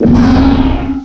cry_not_beartic.aif